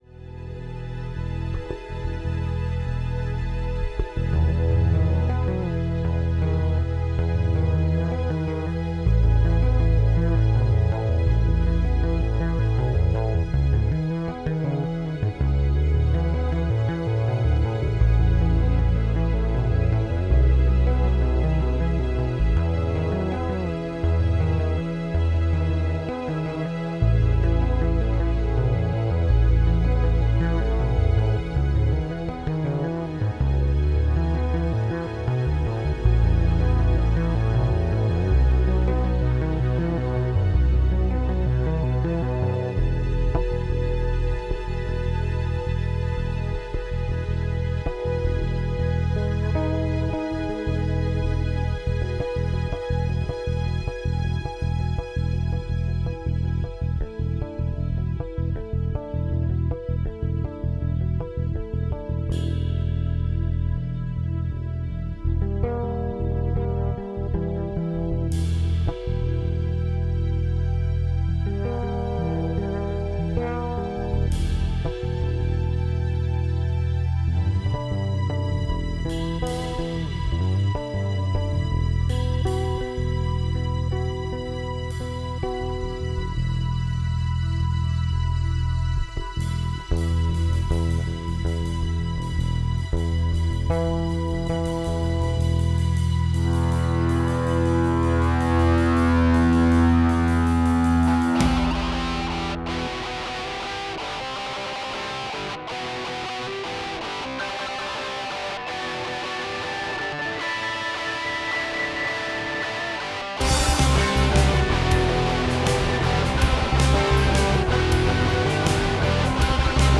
A través de elementos del rock progresivo, busca representar la revelación de Jesús, con una composición musical.
Un silencio, como el que hubo al principio, antes de la irrupción de la Palabra creadora. Notas dulces y luminosas, que son interrumpidas por una disonancia, como el aparecimiento de la corrupción, y que volvería para ser amplificada unos minutos después, como en la crucifixión y que libera nuevamente luz a través de una melodía que alude a la resurrección.
• Técnica: Obra musical de desarrollo progresivo, duración 5 minutos.